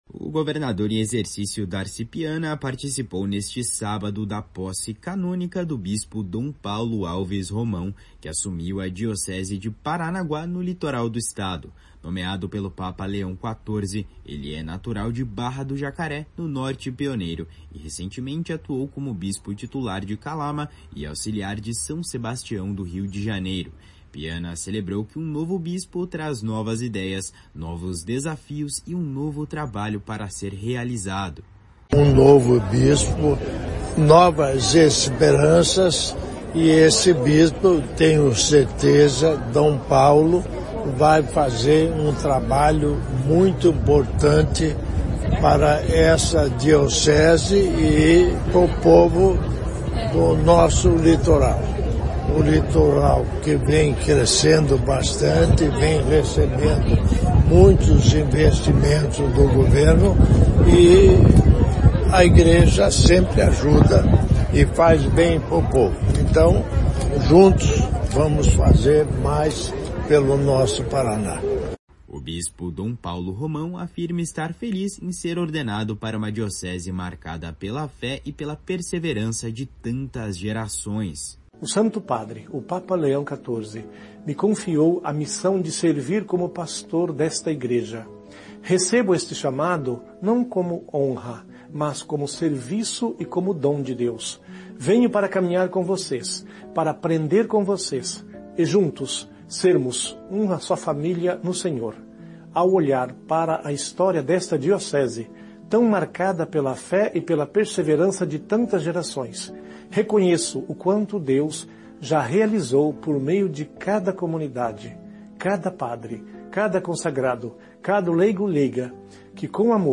// SONORA DARCI PIANA //
// SONORA DOM PAULO ROMÃO //